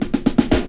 14DR.BREAK.wav